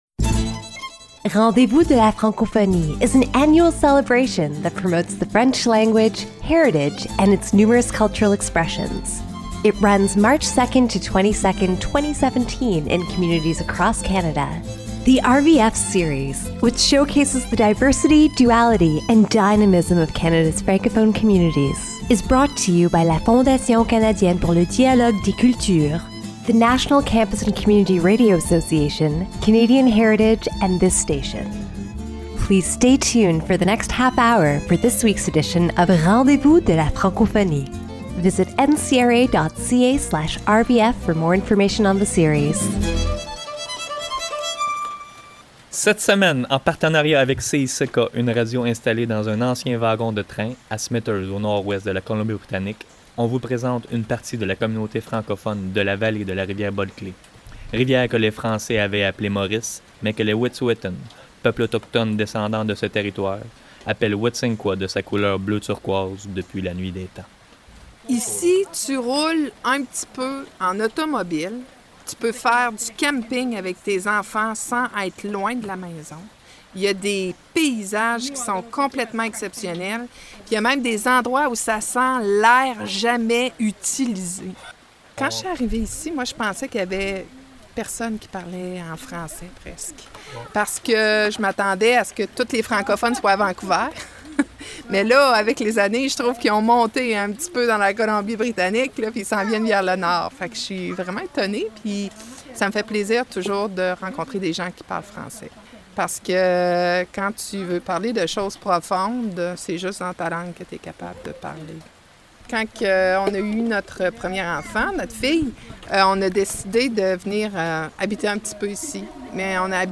This is a documentary about the French community of the Bulkely Valley told by some of it's populati